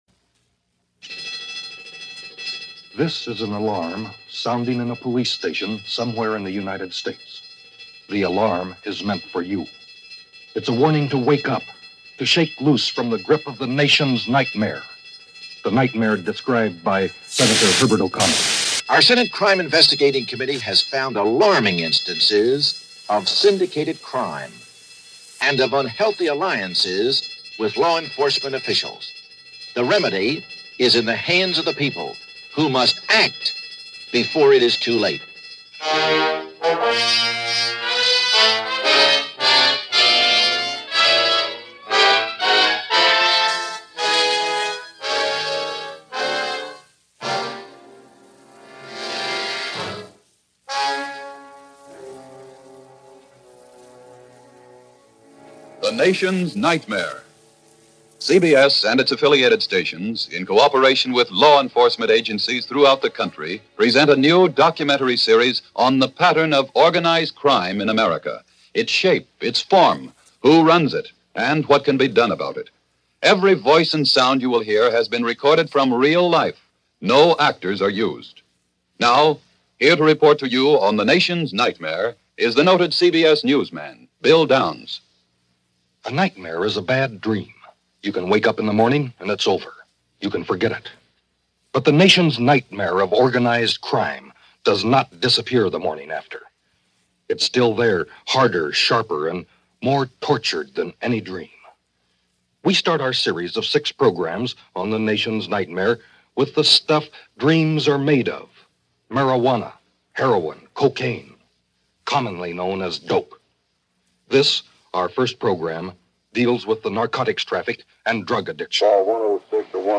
Reports of overdoses were legion – drugs and drug culture were well-known by youth in the 1950s – as was evidenced in this documentary where a reporter asks kids, no older than 13 if they knew what certain drug-related phrases meant.